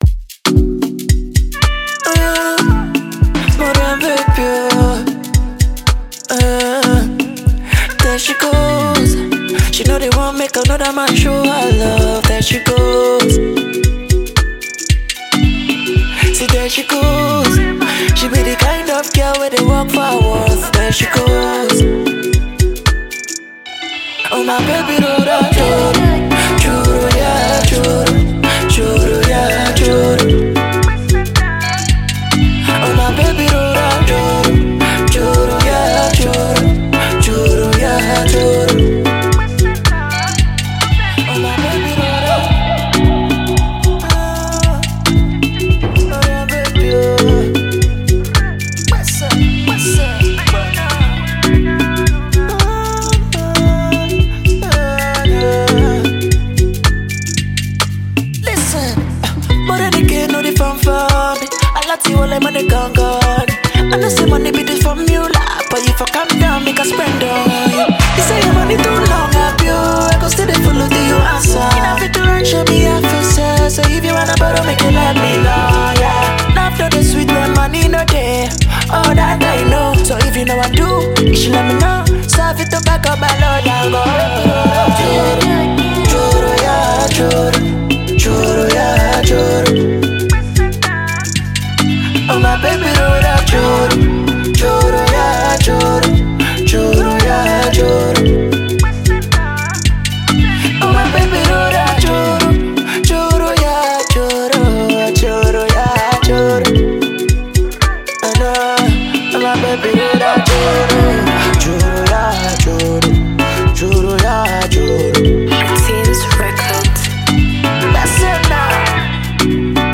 futuristic afro sounds fused with refreshing melodies